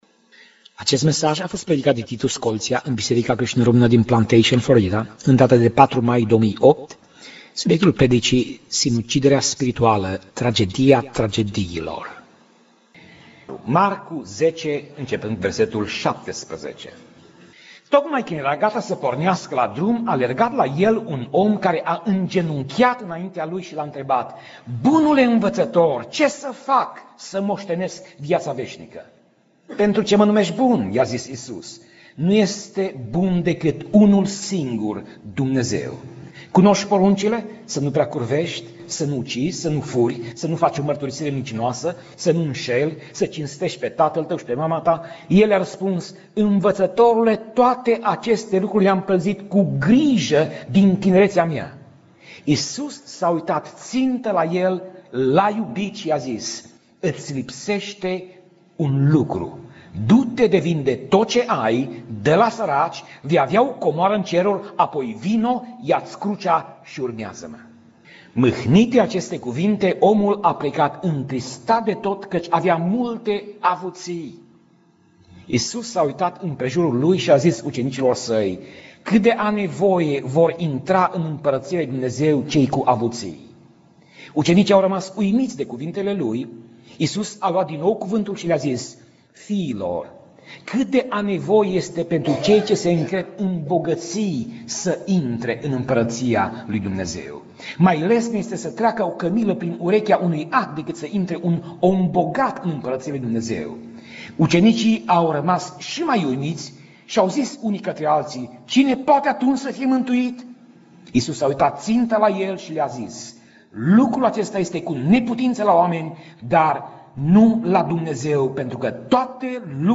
Pasaj Biblie: Marcu 10:17 - Marcu 10:27 Tip Mesaj: Predica